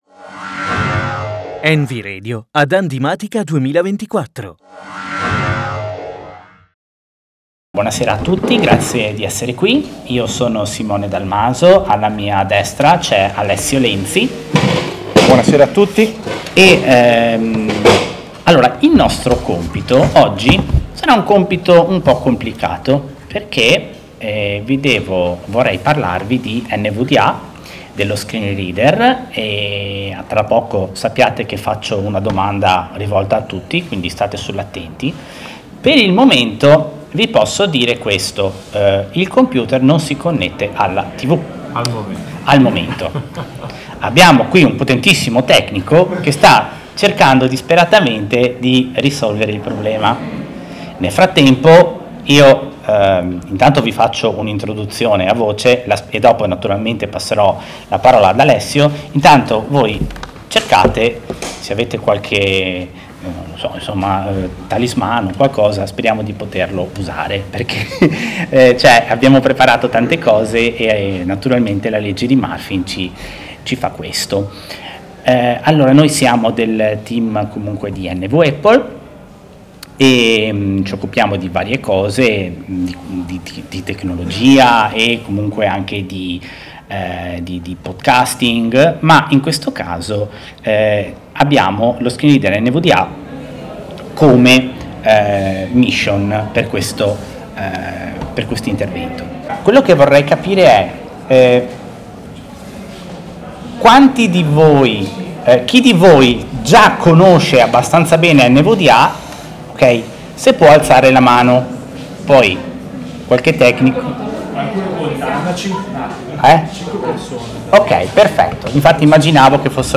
Evento del 29 novembre 2024, presso Handimatica.